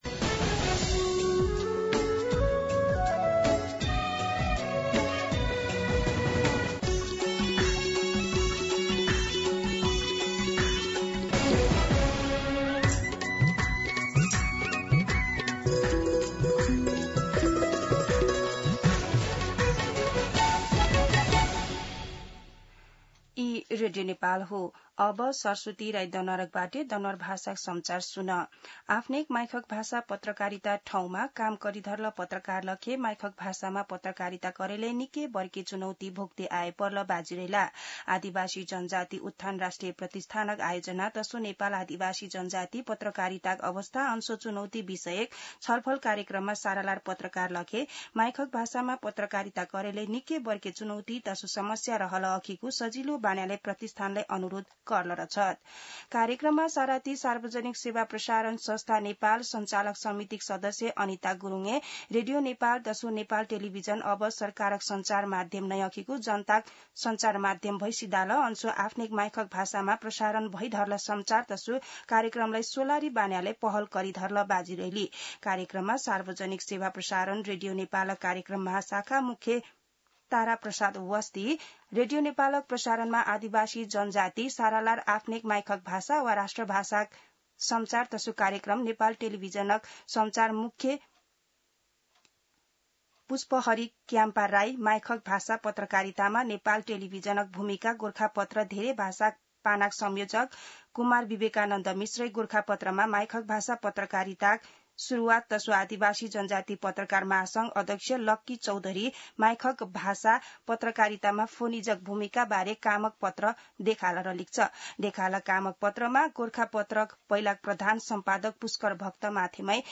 दनुवार भाषामा समाचार : १३ असार , २०८२
Danuwar-News-13.mp3